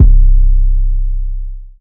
808 (Slaap).wav